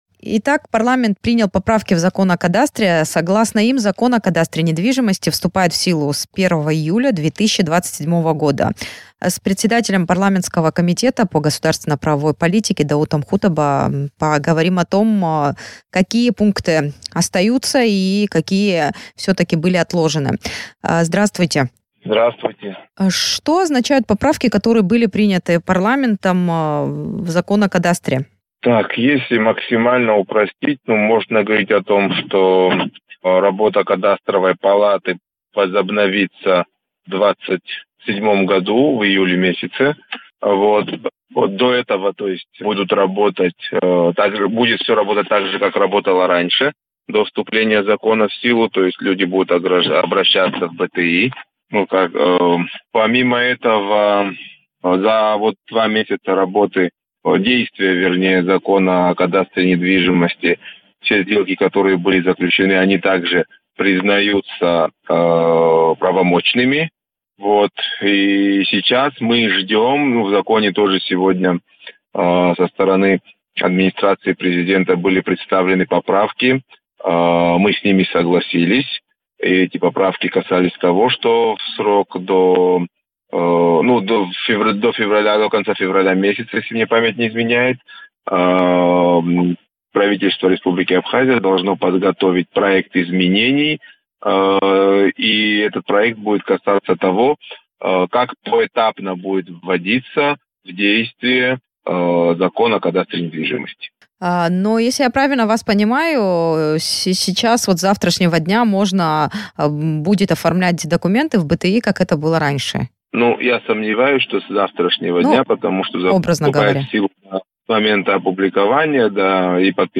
Решения Парламента в эфире радио Sputnik прокомментировал председатель комитета по государственно-правовой политике Даут Хутаба.